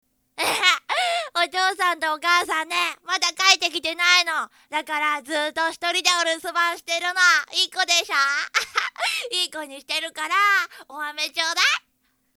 【サンプル♂】
希望を持つ少年